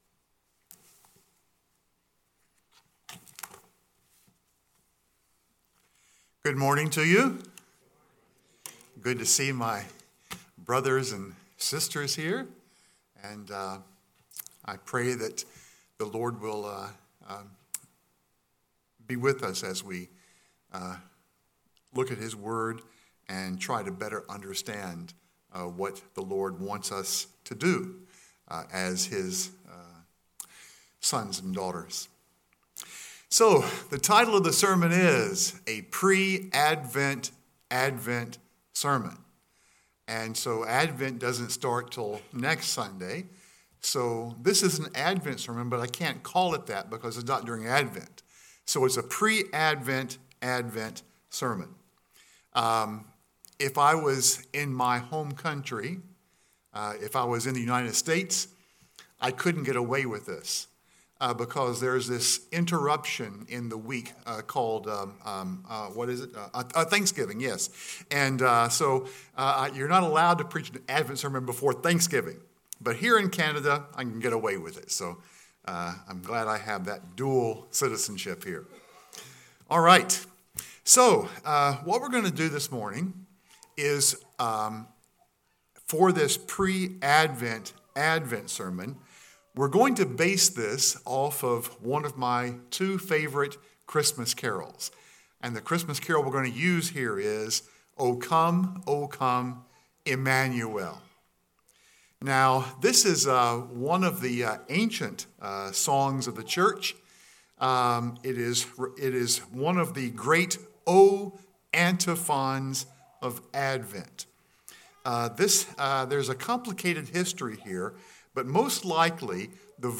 A Pre-Advent Advent Sermon